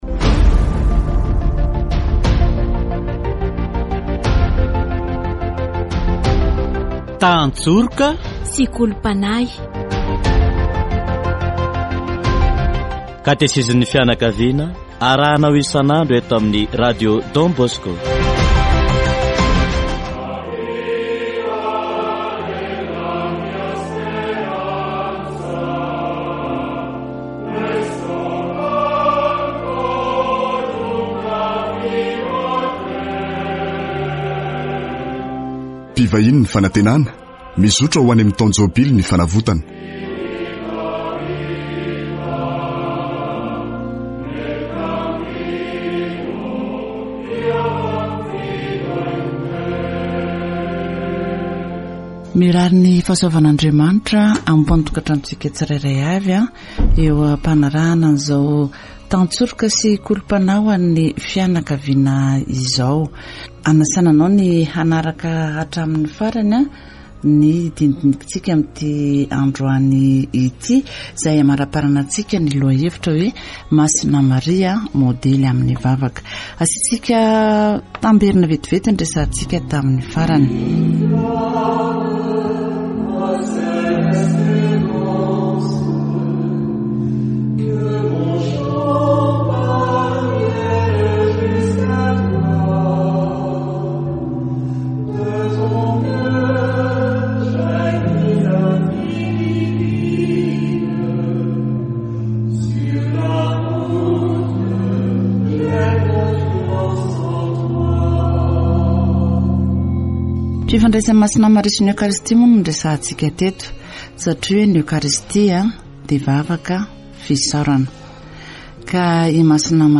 Katesizy momba any Maria, modely amin'ny vavaka